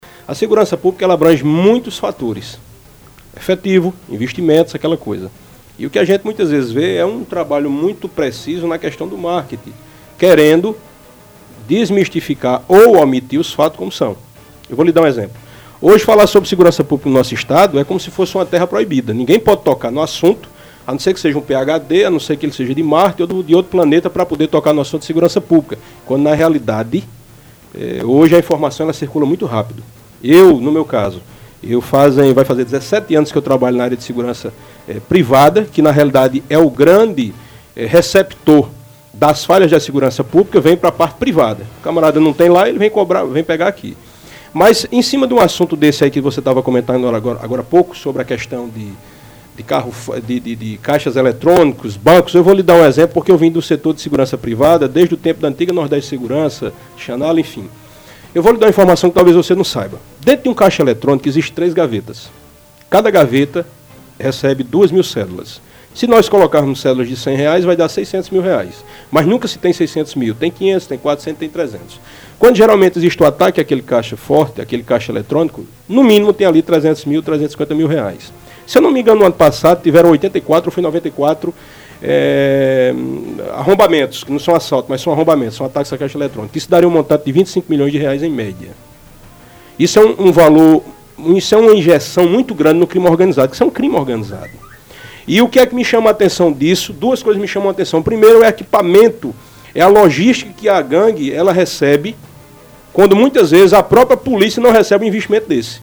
O especialista em segurança pública e privada, Julian Lemos, esteve na tarde desta quarta-feira (02) no programa Debate Sem Censura, da rádio Sanhauá.